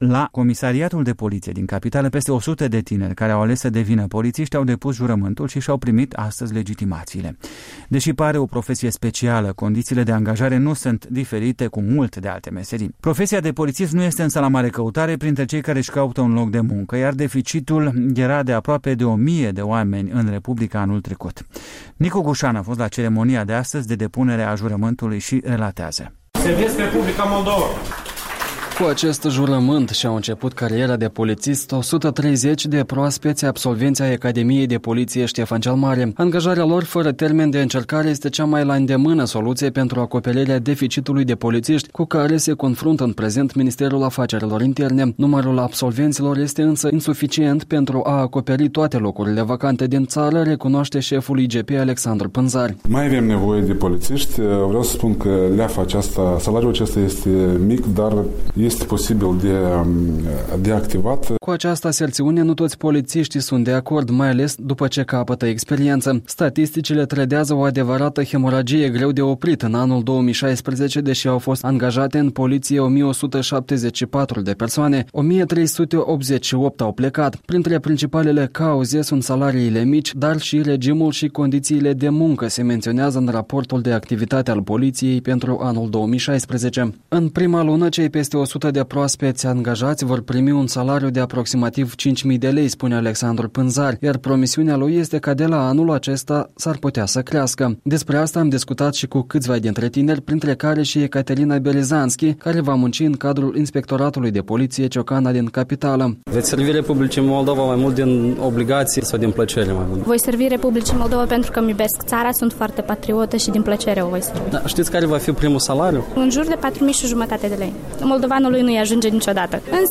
Profesia de polițist nu este însă la mare căutare printre cei care-și caută un loc de muncă, iar deficitul de polițiști anul trecut în republică era de aproape o mie de oameni. Mai multe detalii de la ceremonia de depunere a jurământului a tinerilor polițiști:
Am întrebat câțiva trecători de pe străzile capitalei ce cred ei despre activitatea polițiștilor: